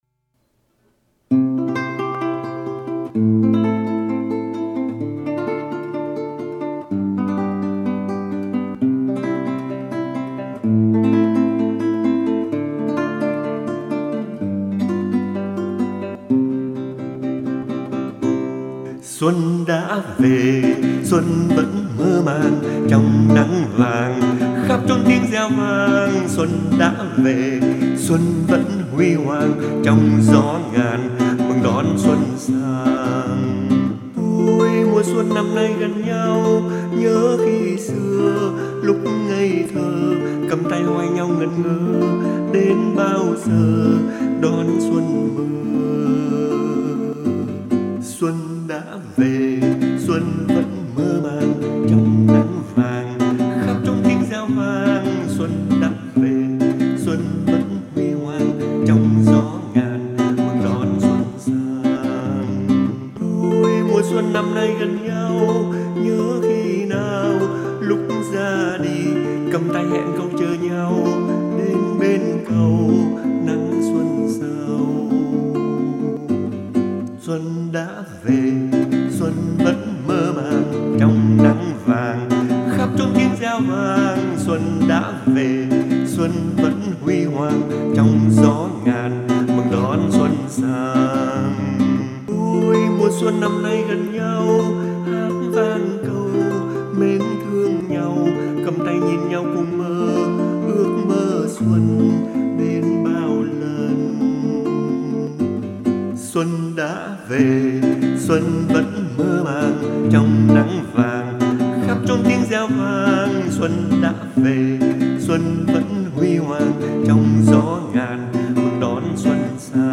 Mambo, một điệu nhảy nhộn nhịp của Châu Mỹ La-tinh